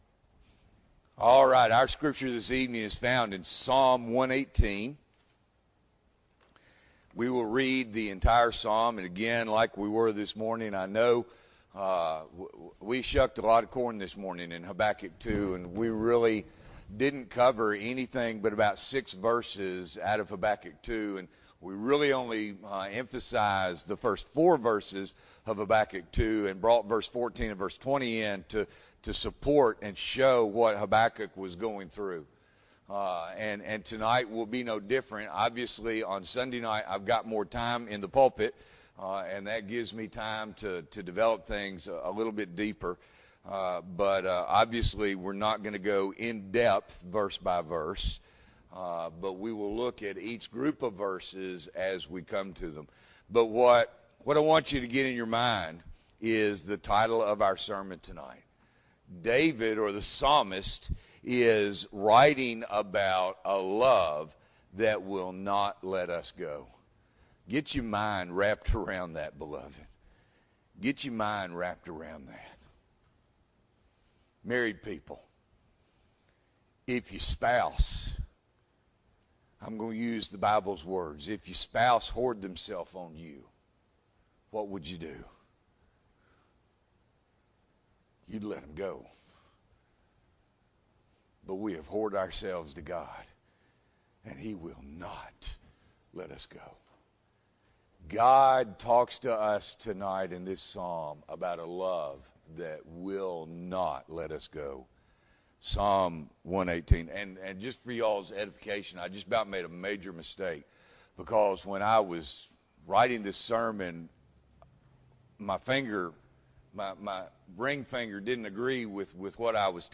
July 25, 2021 – Evening Worship